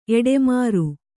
♪ eḍemāru